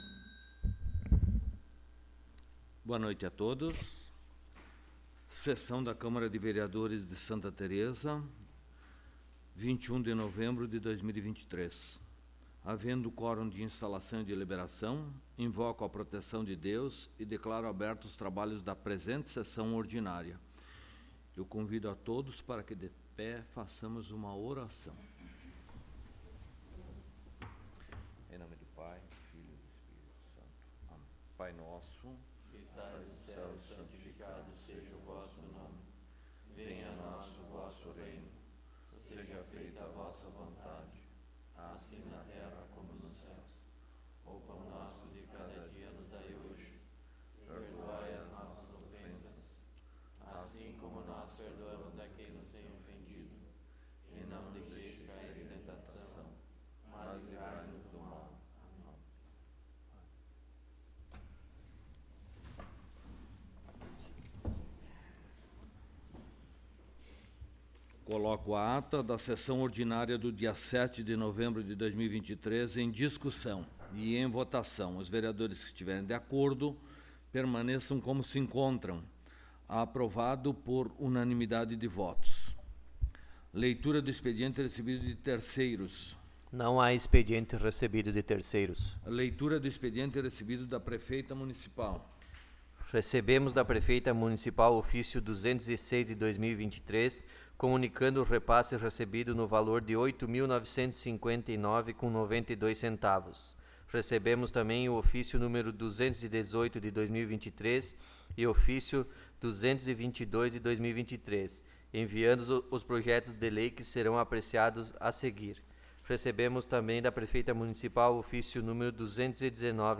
Áudio da Sessão
Local: Câmara Municipal de Vereadores de Santa Tereza